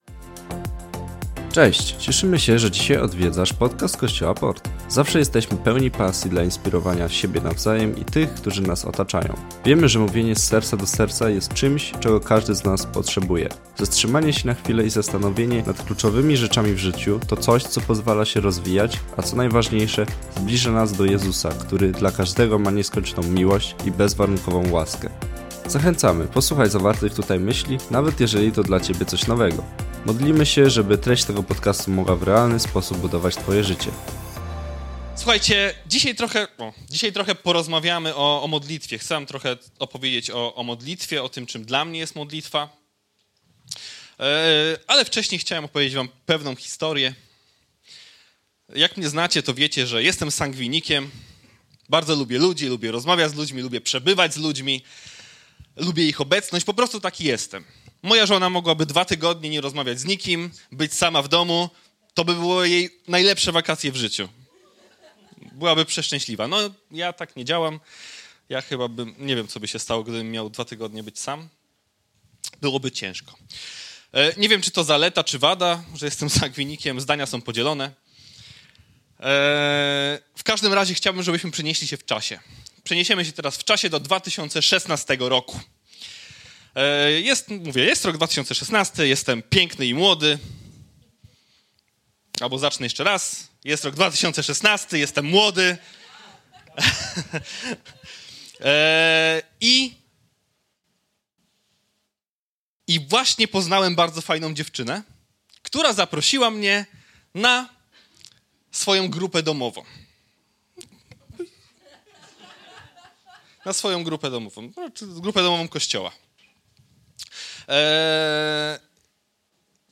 Pobądź z nami przez kolejne minuty i posłuchaj kazania jednego z naszych liderów o tym, jak możesz się modlić i przez tę modlitwę doświadczać zmiany.